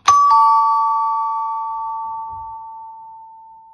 Juhtmega uksekell P57102
kõrgeim helisemistugevus 85 dB
helinate arv 1
Meloodia (MP3)